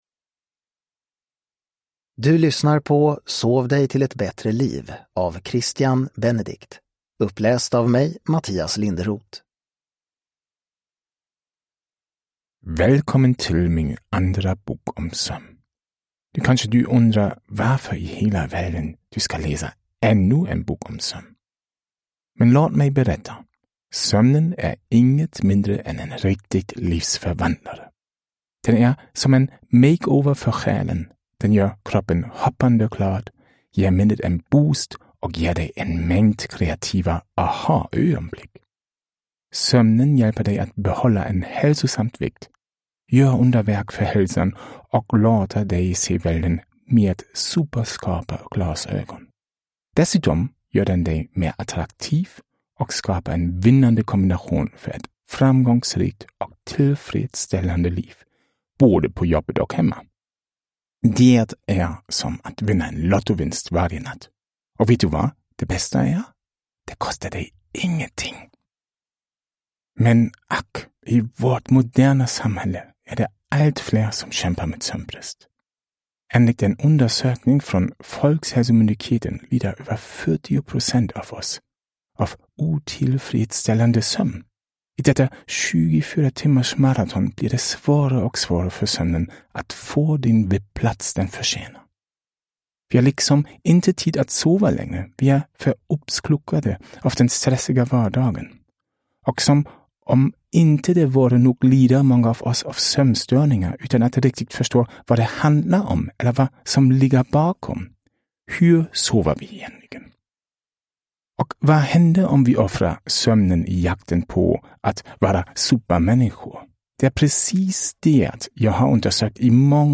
Sov dig till ett bättre liv : vad andning, sex, melatonin, tyngdtäcke, fullmåne och kiwi kan göra för din sömn – Ljudbok – Laddas ner